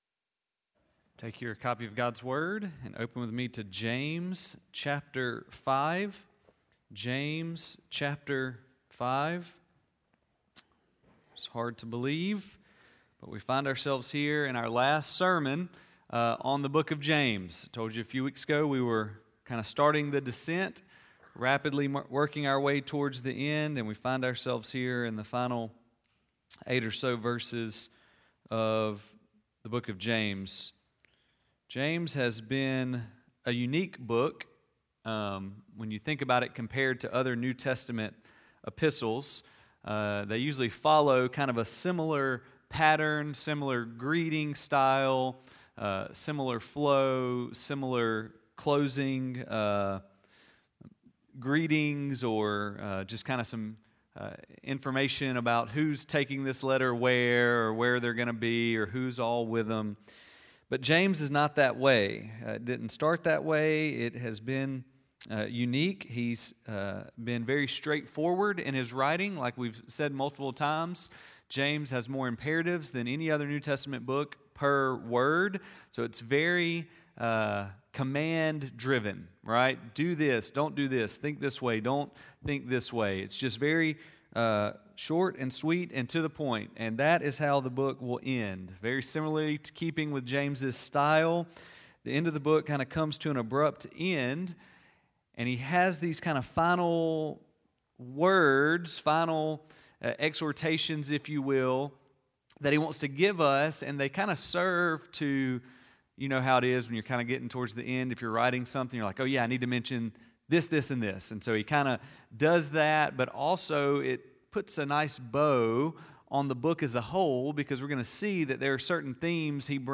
Sermon Series: James || Scripture Reference: James 5:13-20